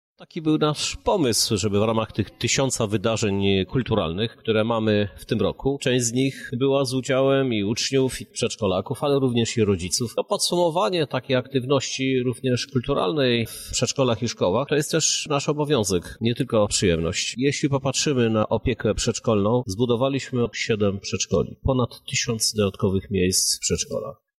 O zaangażowaniu młodych lublinian mówi prezydent miasta, Krzysztof Żuk.